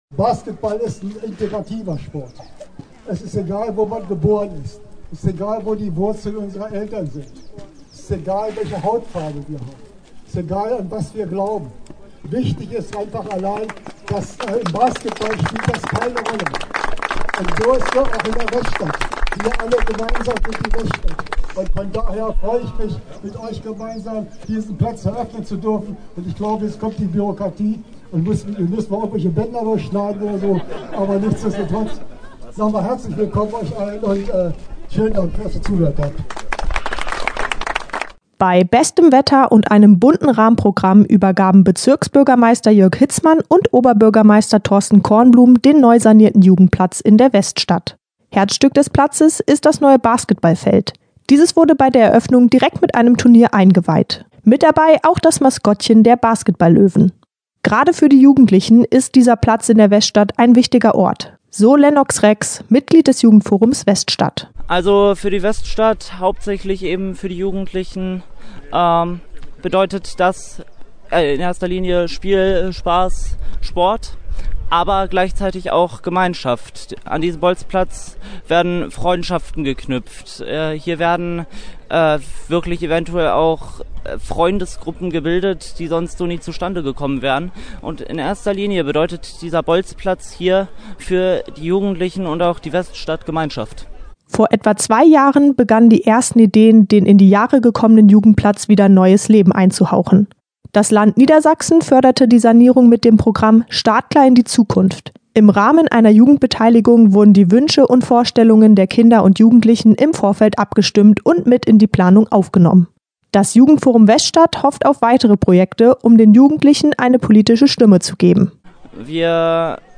Dieser Tage wurde der neu gestaltete Jugendplatz an der Swinestraße in der Braunschweiger Weststadt eröffnet. Der Neustart wurde mit einem bunten Fest gefeiert.